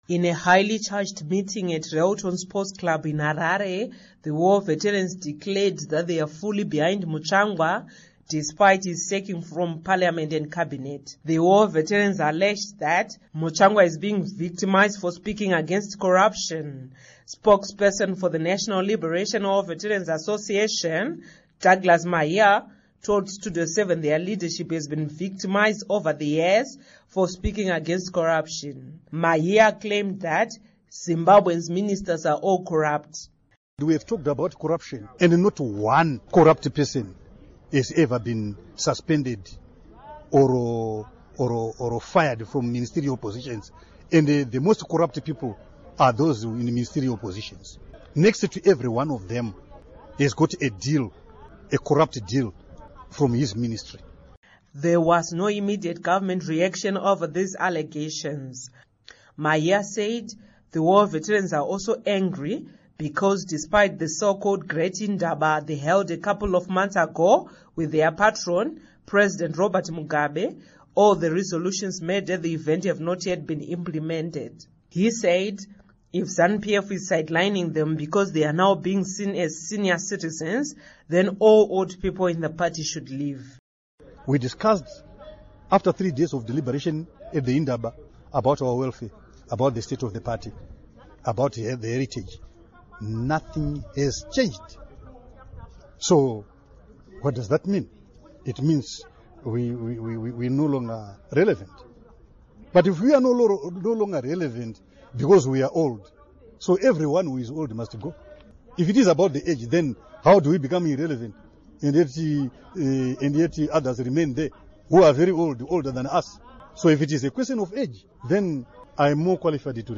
Report on War Vets